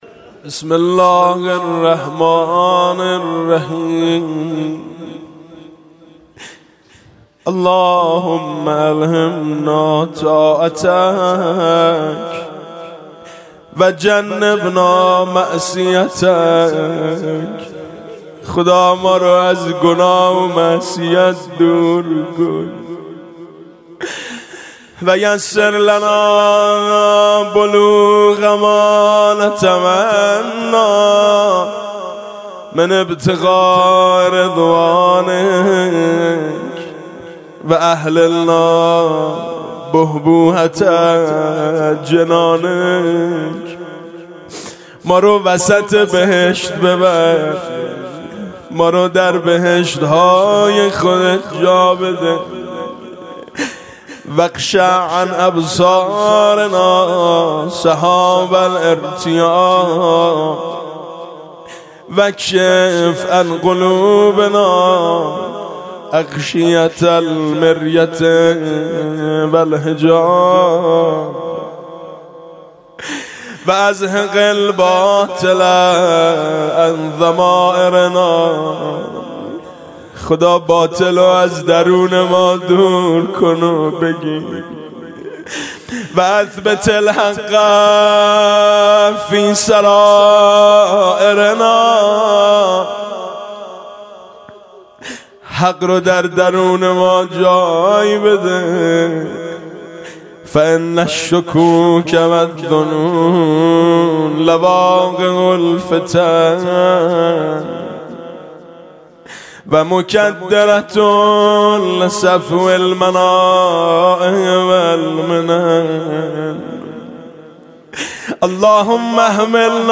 صوت/ "مناجات المطیعین" با نوای میثم مطیعی